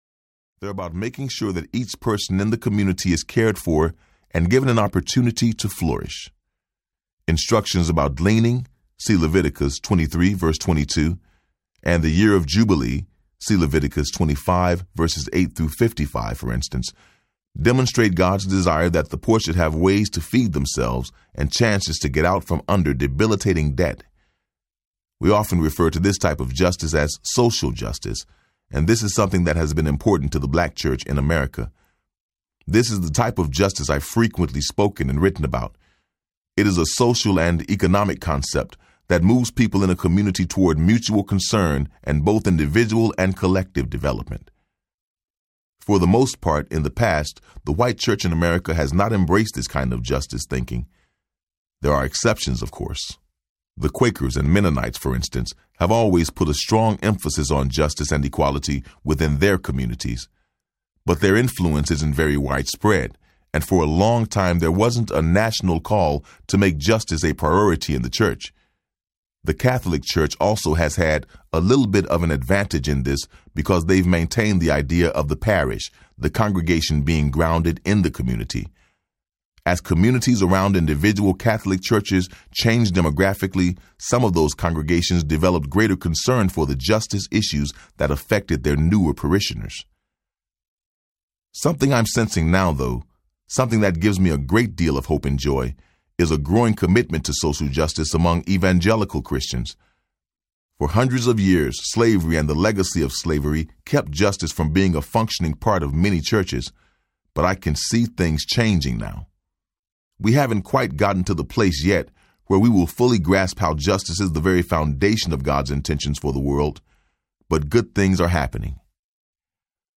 Dream With Me Audiobook
Narrator
6.35 Hrs. – Unabridged